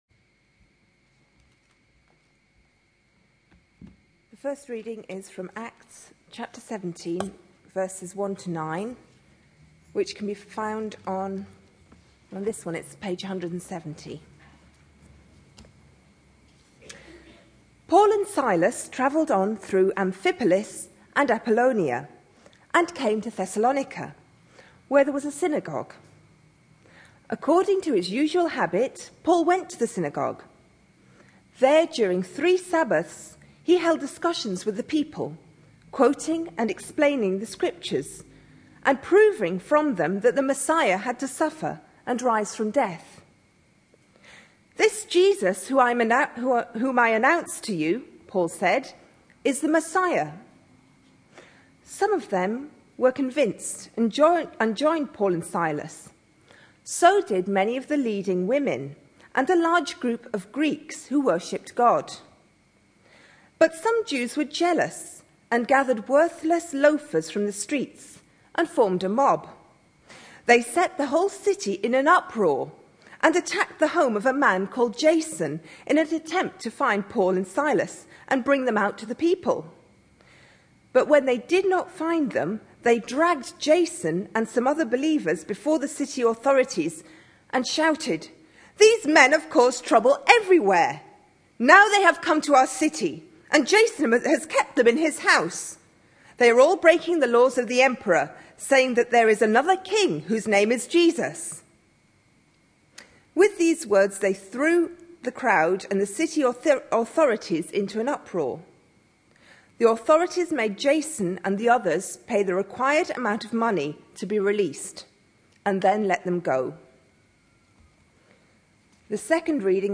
A sermon preached on 19th June, 2011, as part of our God at Work in..... series.